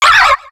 Cri de Carabing dans Pokémon X et Y.